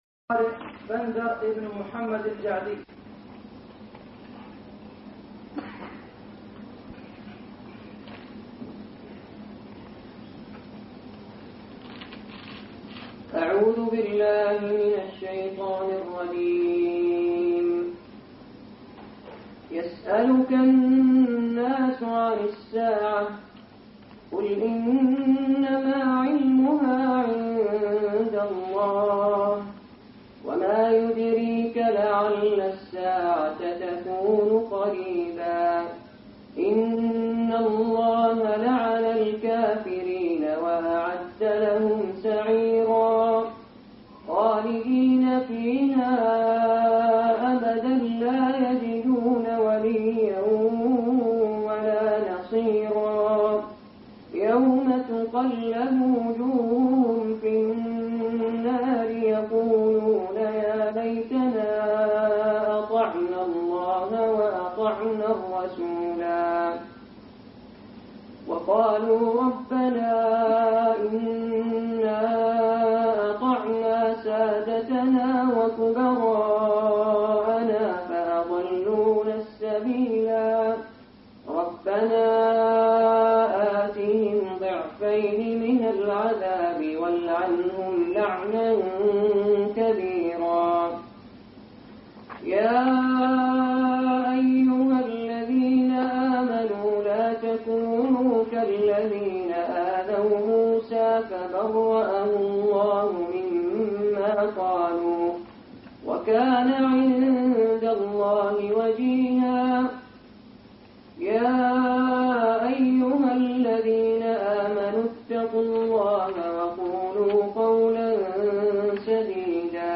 الندوة السنوية للإعجاز العلمي - الدكتور زغلول النجار